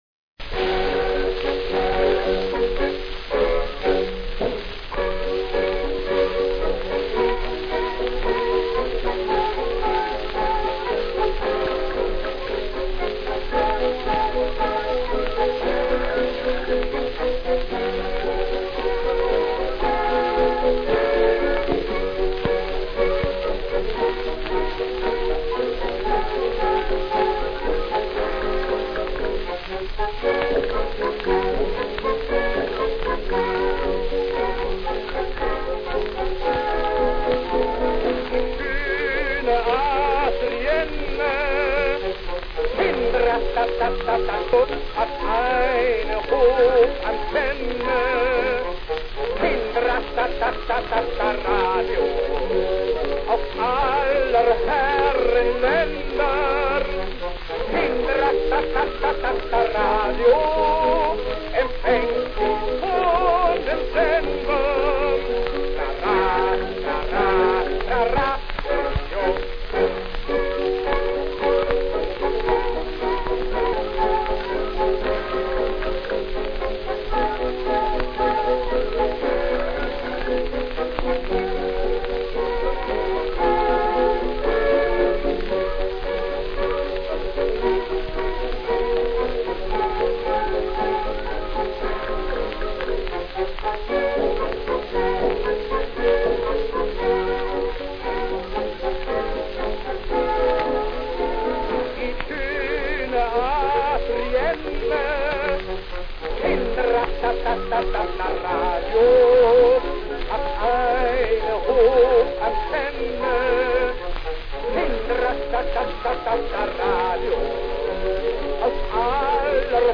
ein Foxtrott